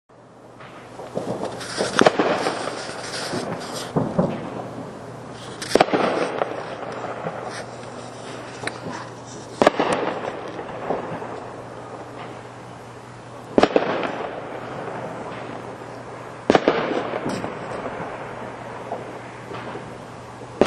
Sounds of the northwest suburbs in the seconds after midnight, New Year’s Day 2025.